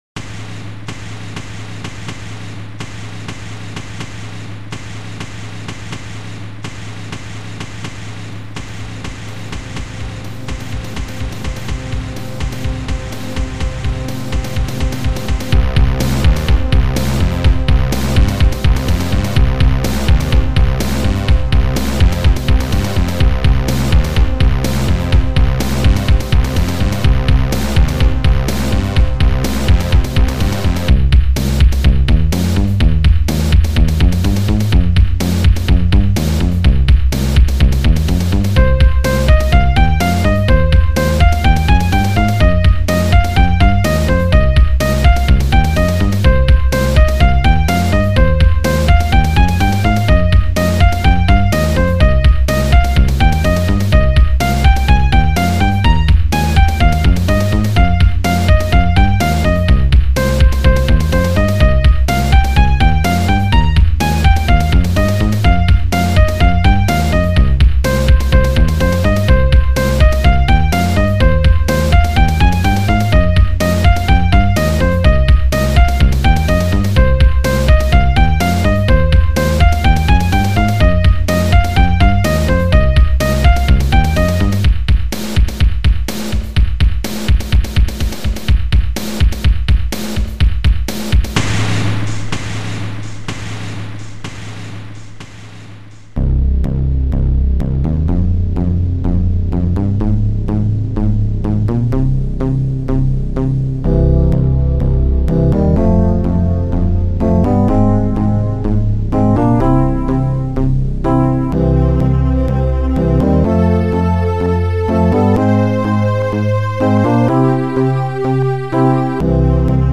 auf einem Amiga 500 & Amiga 4000.